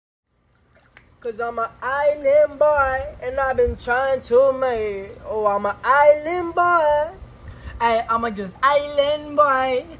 boy.mp3